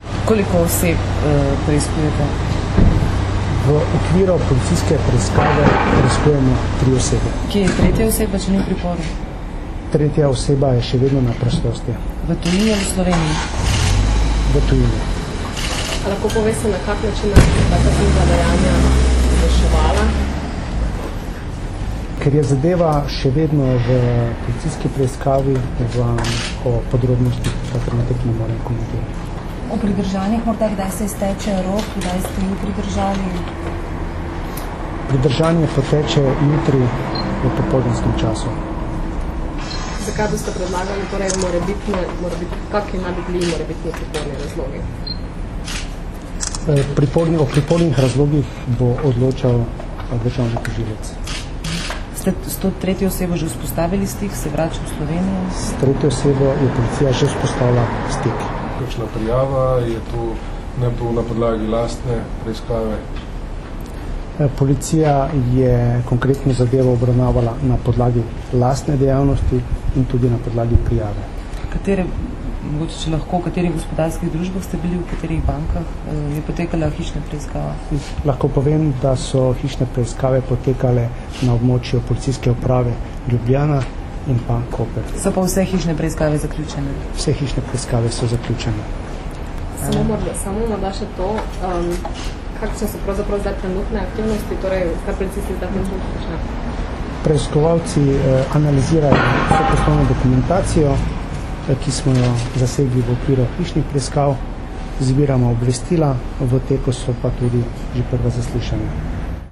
Novinarska vprašanja in odgovori nanje (mp3)